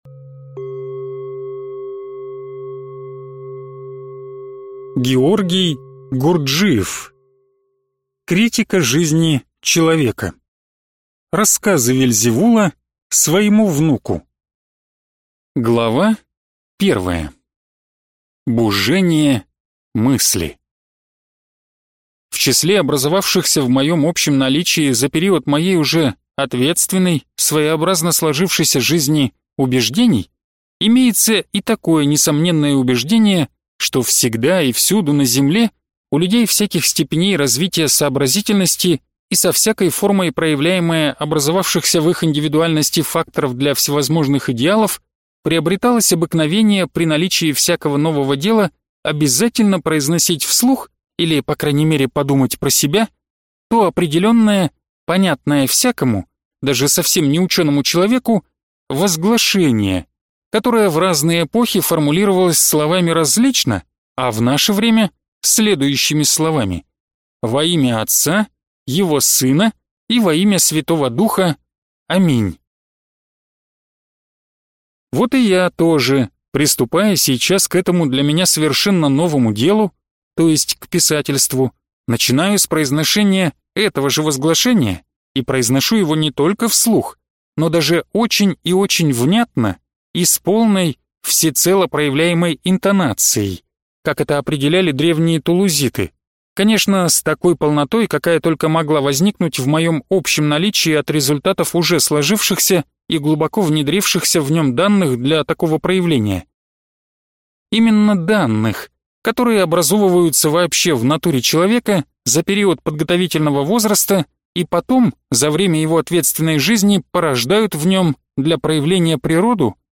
Аудиокнига Критика жизни человека. Рассказы Вельзевула своему внуку (Часть 1) | Библиотека аудиокниг